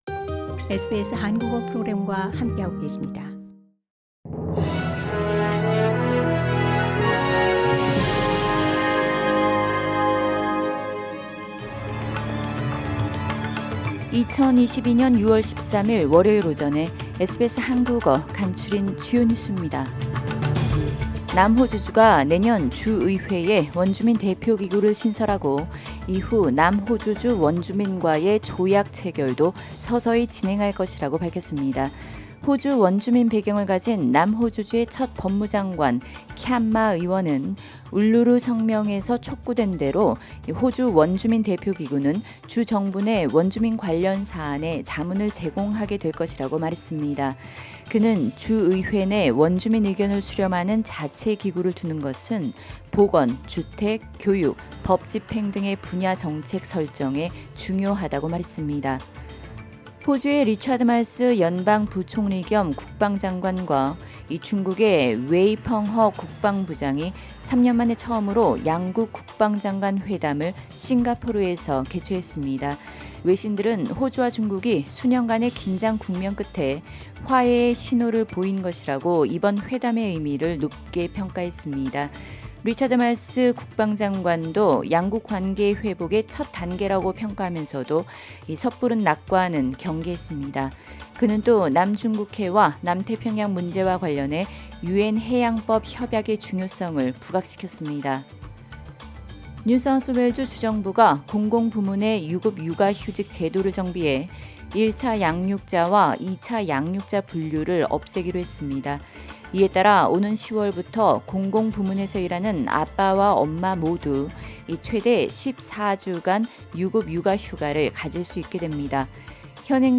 2022년 6월 13일 월요일 아침 SBS 한국어 간추린 주요 뉴스입니다.